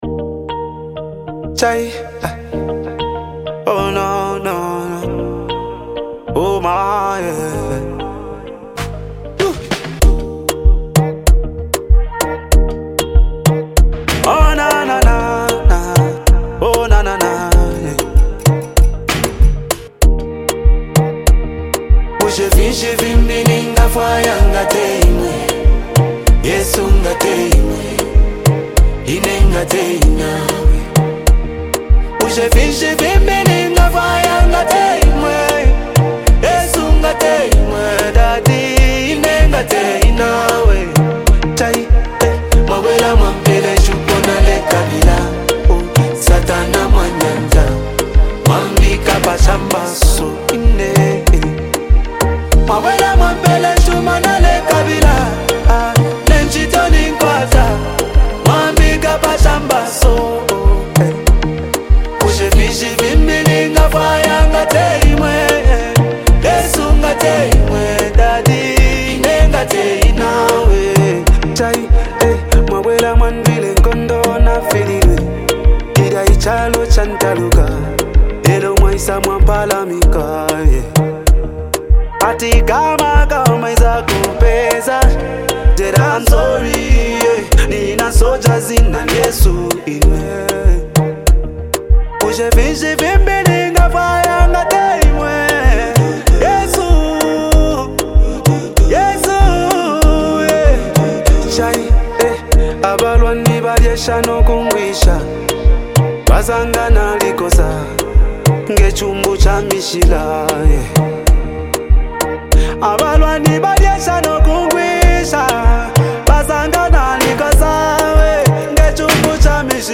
combines rich harmonies and dynamic instrumentation